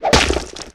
tentackle.ogg